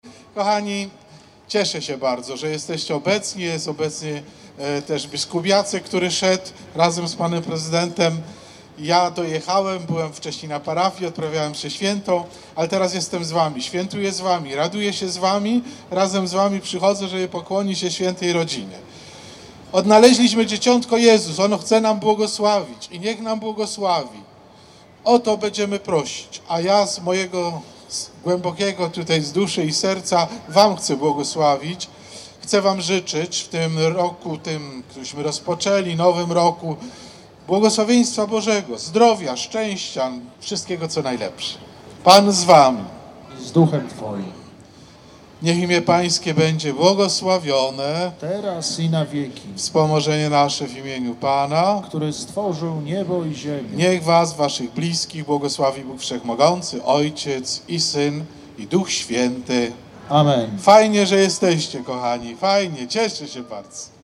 Po raz 13. przez Wrocław przeszedł Orszak Trzech Króli.